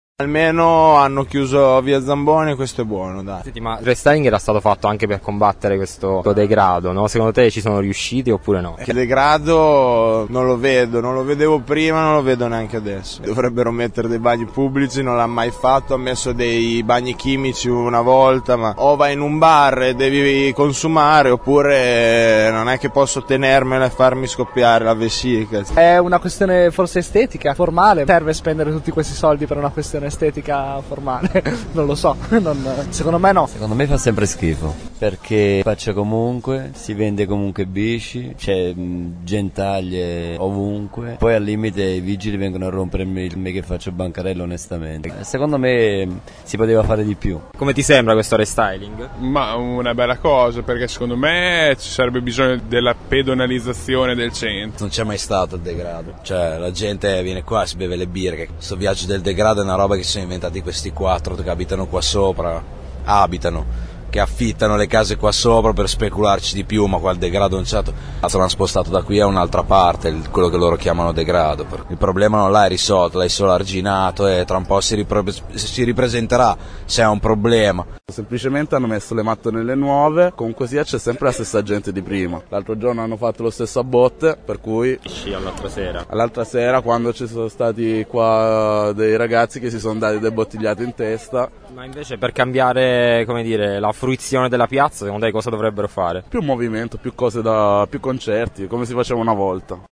Piccolo viaggio nella piazza più discussa della città dopo la ripavimentazione e la chiusura al traffico di via Zamboni.